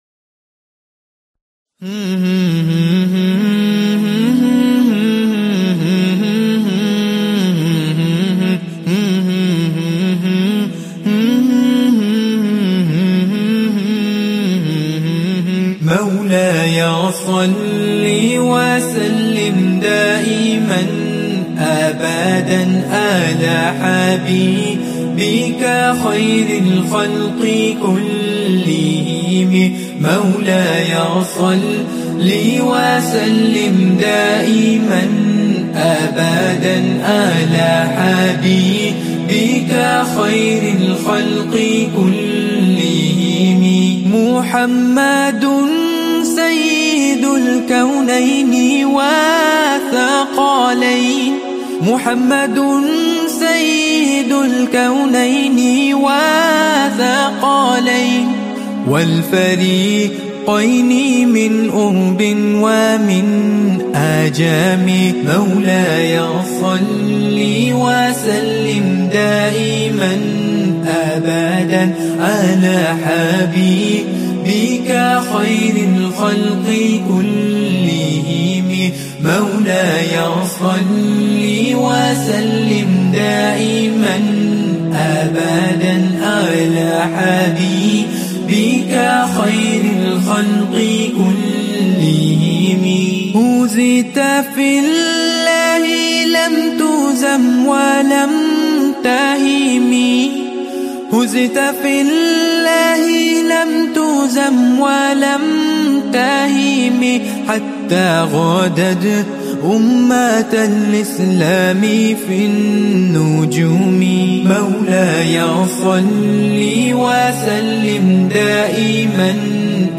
Relax with this beautiful Naat.